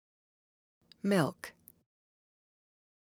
Word: Milk (Female Voice)
The word "Milk" spoken by a female voice
Recording Location: (In Studio)